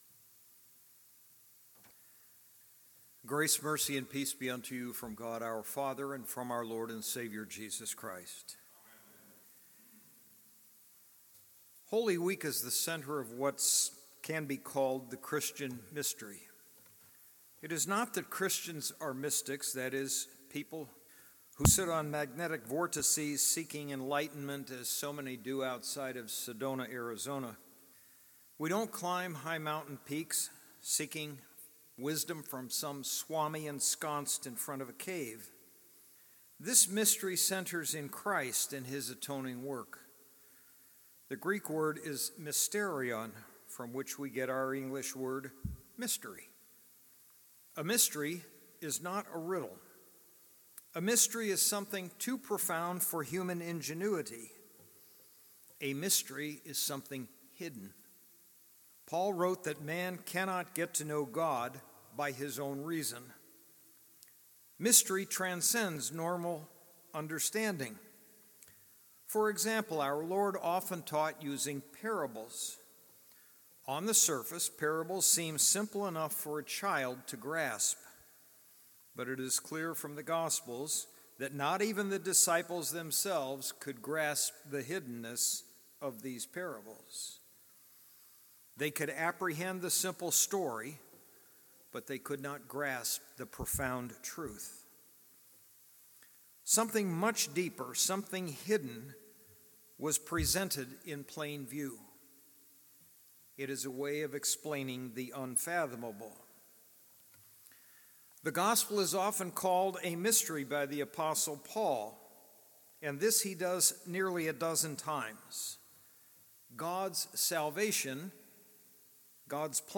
PALM SUNDAY/SUNDAY of the PASSION